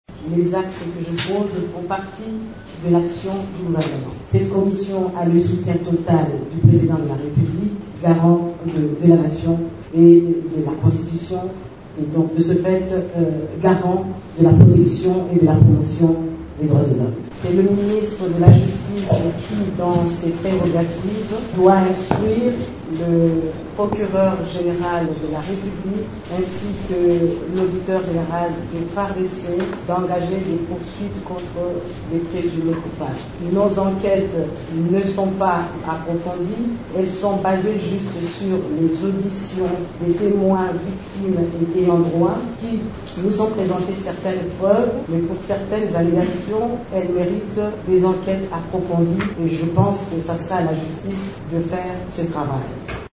Vous pouvez écouter la ministre des droits humainss dans cet extrait sonore: